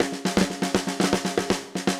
Index of /musicradar/80s-heat-samples/120bpm
AM_MiliSnareA_120-02.wav